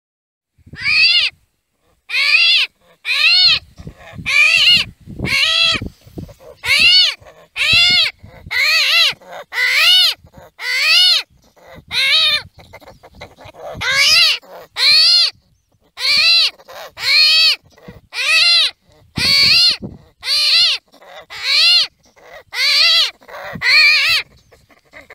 Звук с криком броненосца